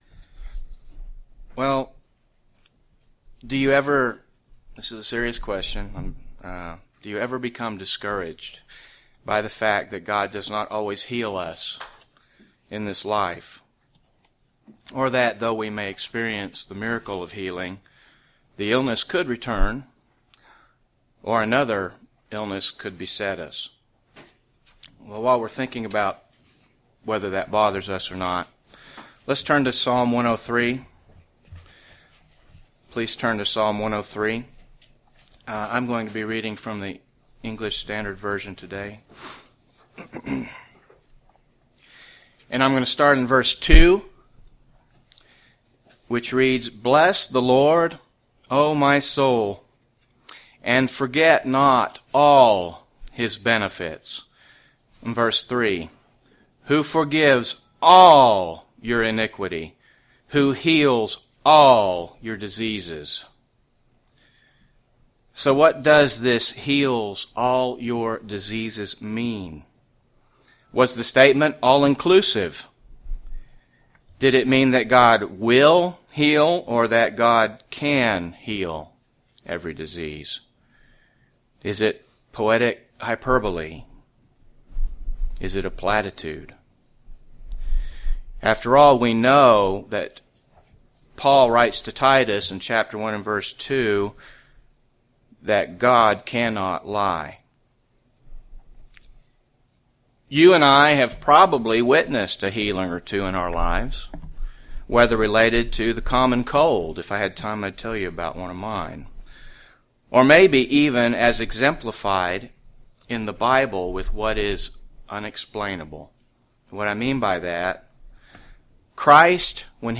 UCG Sermon spiritual healing the process of conversion Notes Presenter's Notes Do you ever become discouraged by the fact that God does not always heal us in this life?